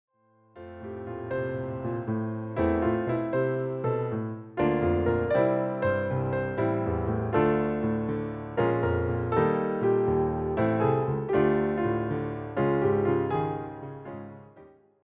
solo piano takes on Broadway material